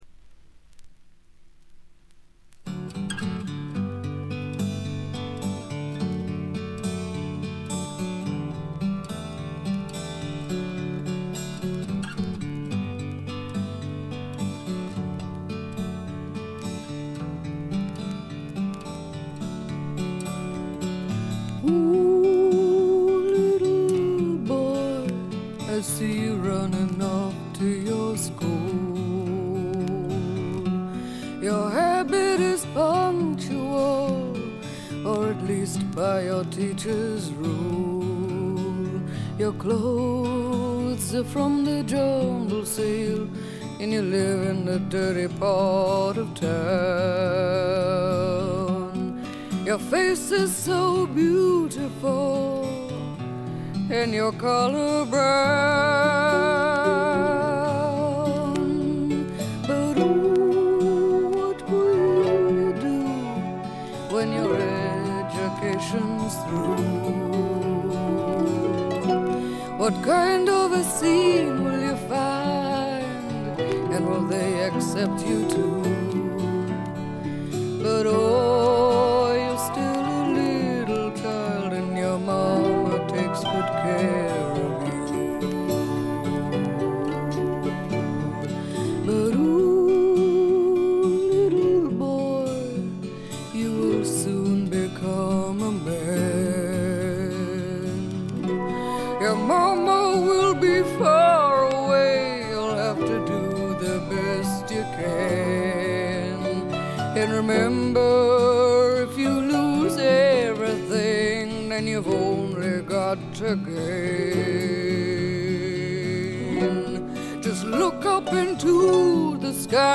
試聴曲は現品からの取り込み音源です。
Recorded at Nova Sound Recording Studios, London